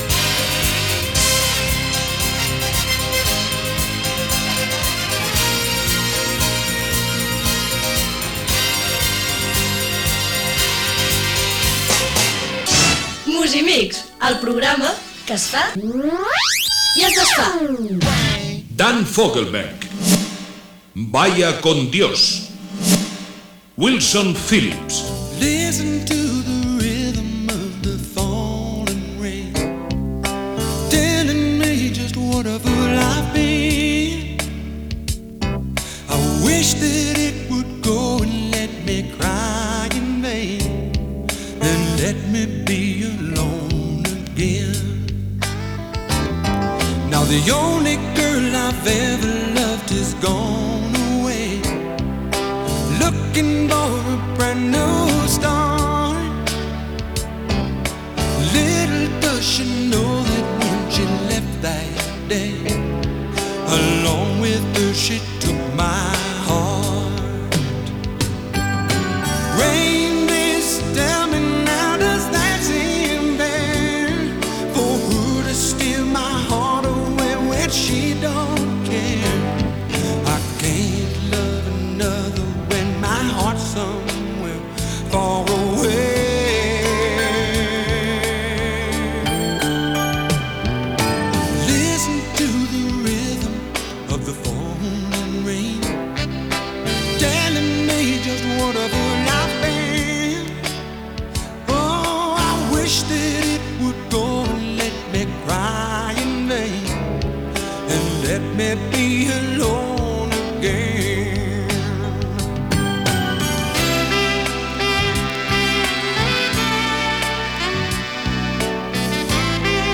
Nom programa Musimix Descripció Indicatiu del programa, noms dels artites i temes musicals Gènere radiofònic Musical